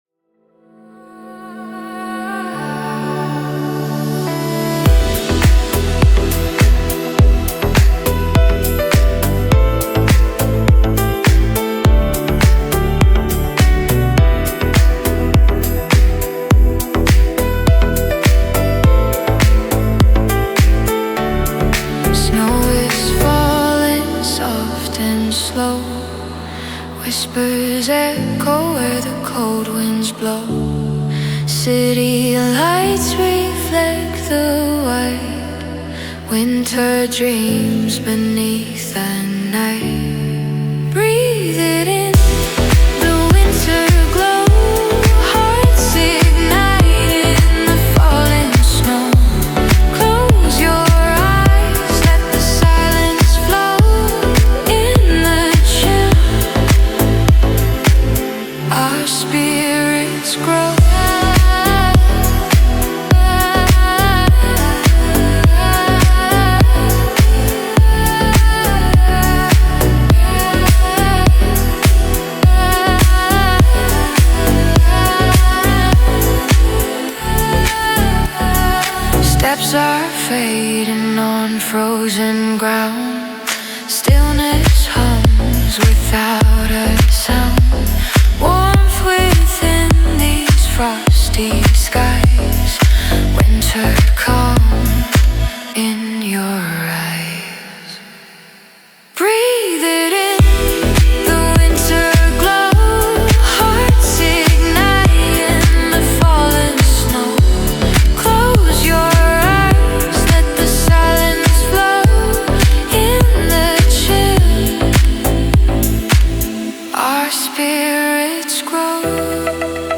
Атмосферная композиция, которая передает магию зимней ночи.